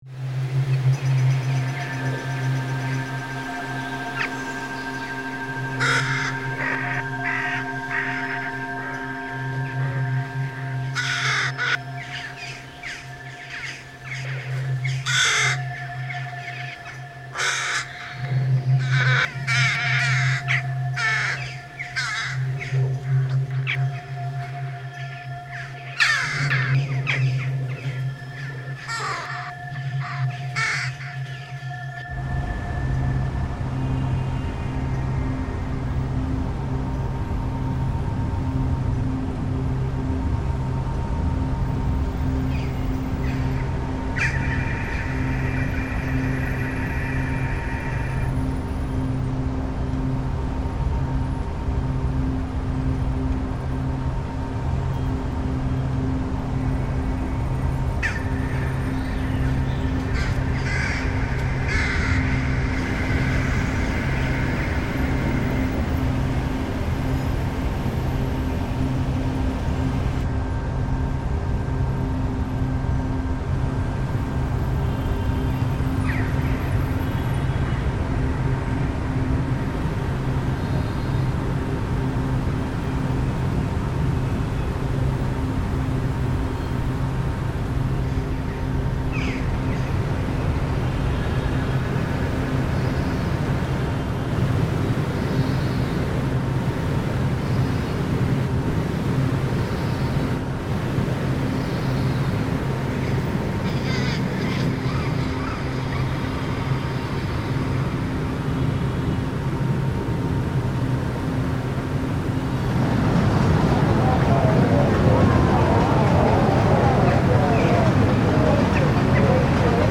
Corvid roost reimagined